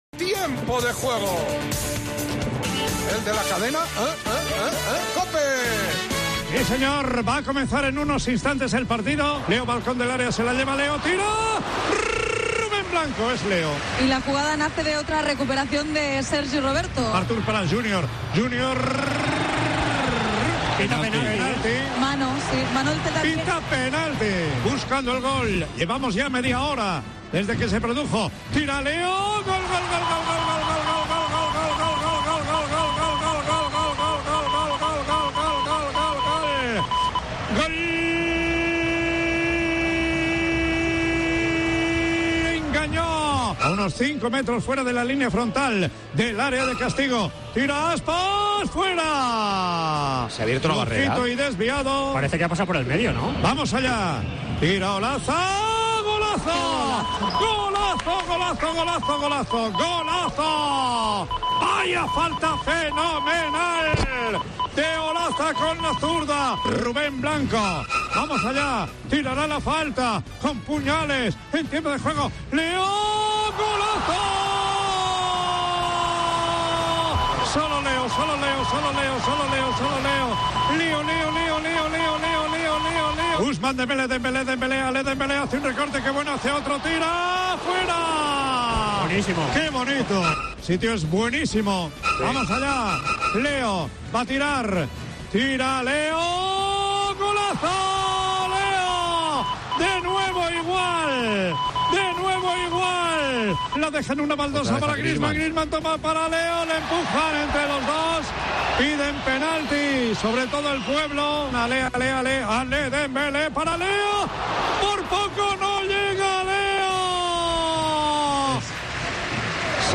Així van sonar els gols del Barça 4-Celta 1